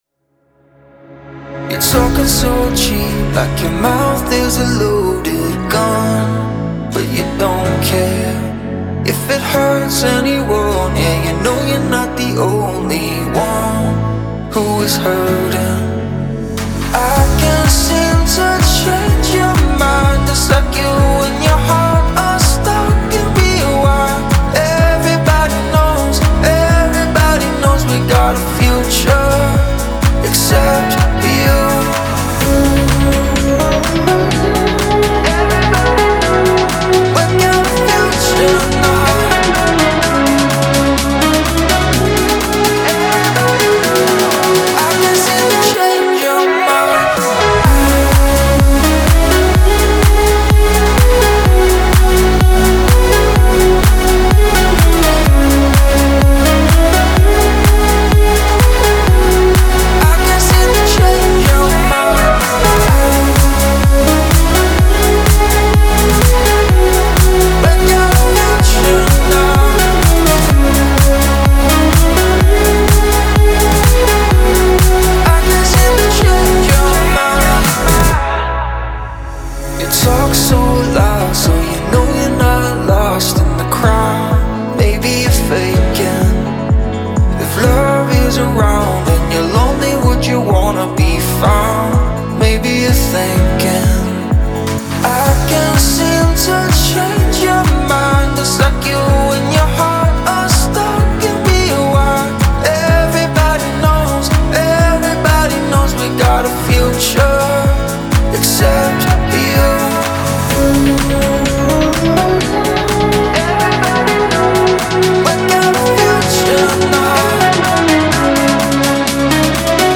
dance
pop
диско